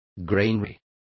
Complete with pronunciation of the translation of granaries.